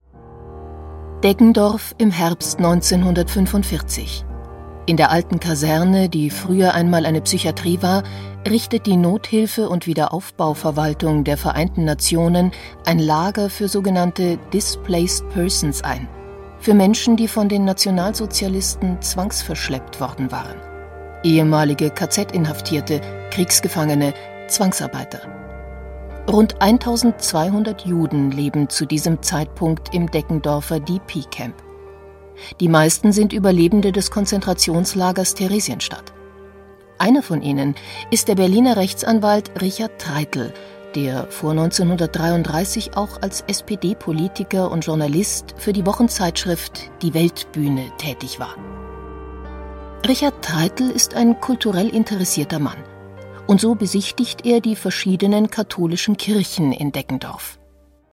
Booking Sprecherin
Sprecherin, Werbesprecherin, Schauspielerin, Stationvoice, Moderatorin